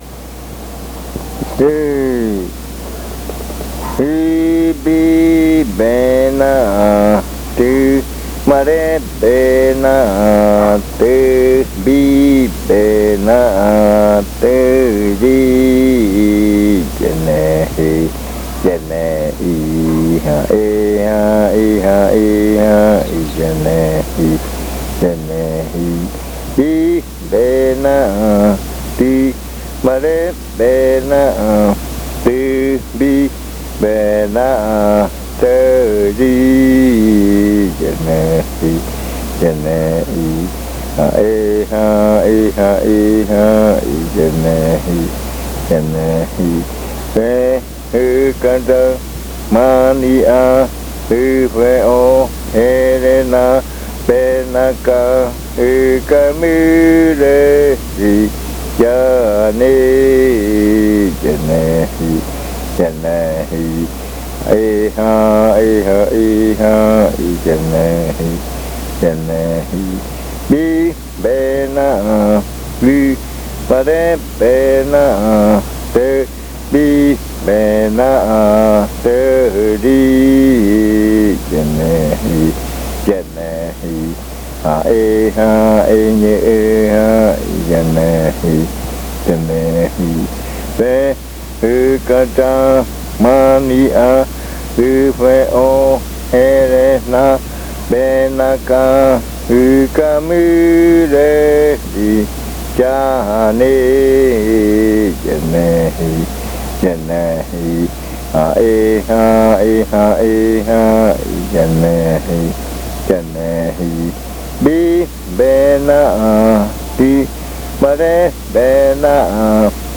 Leticia, Amazonas
Este canto hace parte de la colección de cantos del ritual Yuakɨ Murui-Muina (ritual de frutas) del pueblo Murui
This chant is part of the collection of chants from the Yuakɨ Murui-Muina (fruit ritual) of the Murui people